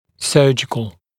[‘sɜːʤɪkl][‘сё:джикл]хирургический